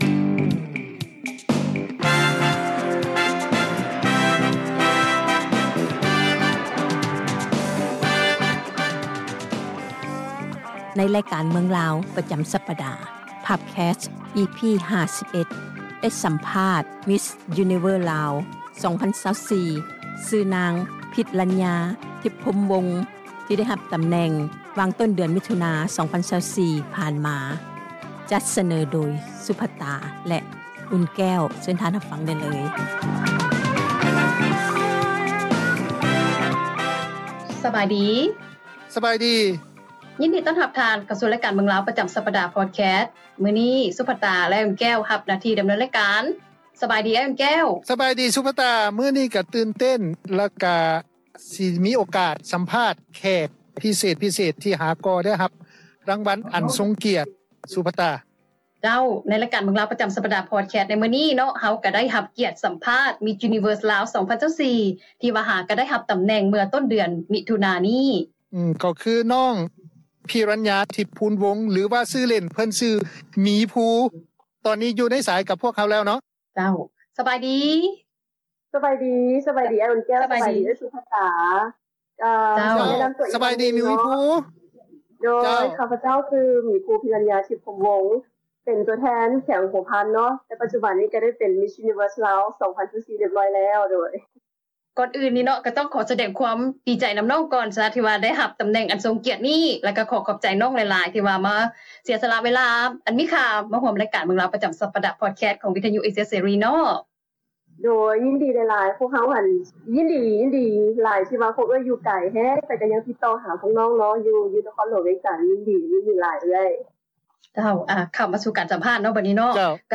ສໍາພາດ MISS UNIVERSE LAOS 2024